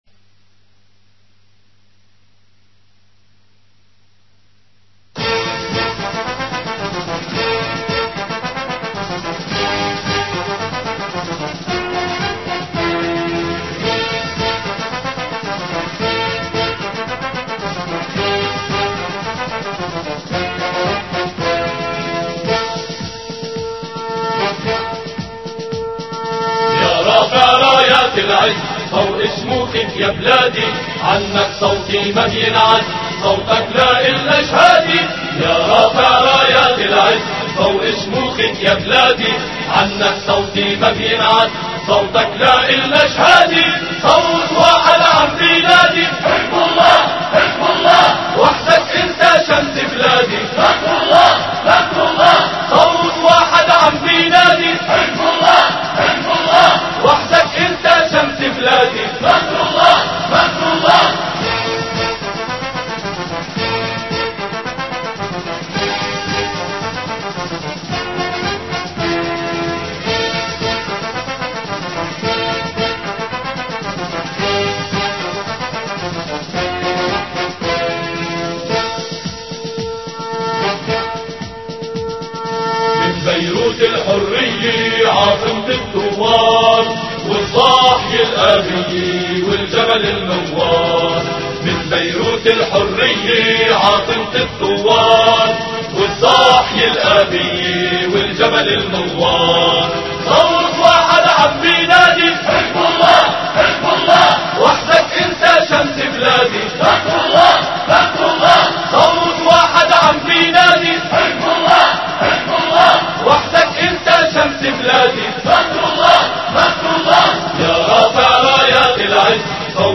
أناشيد لبنانية